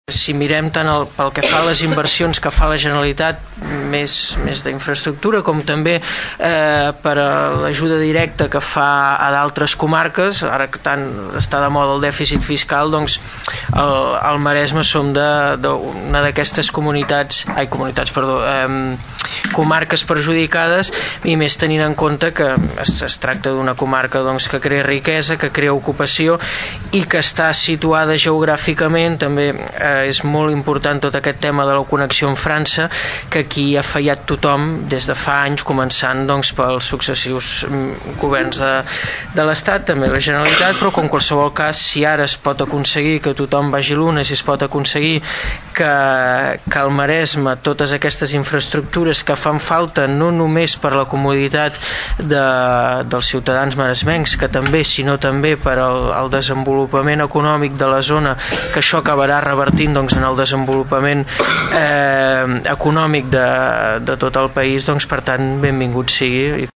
El regidor del PP, Xavier Martín, recordava durant la seva intervenció al plenari que aquesta qüestió fa anys que es treballa des del Consell Comarcal del Maresme. Diu que és una de les comarques més perjudicades en inversions, tot i comptar amb una situació estratègica.